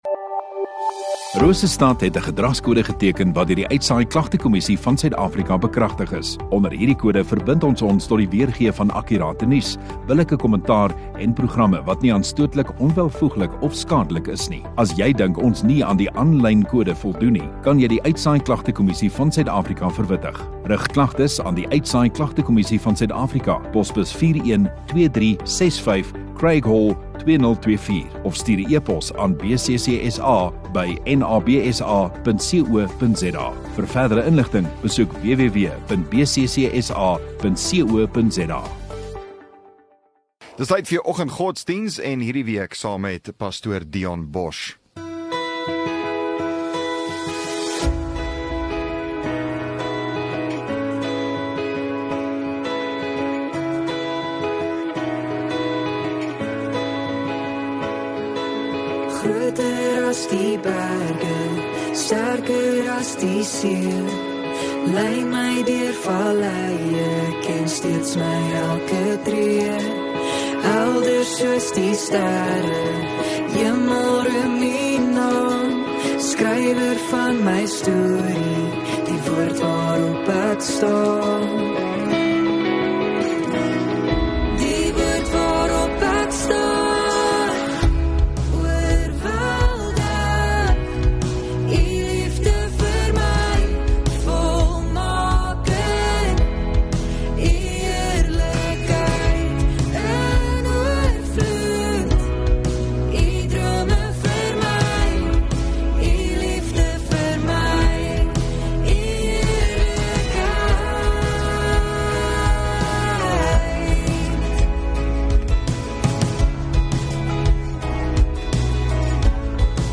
11 Apr Vrydag Oggenddiens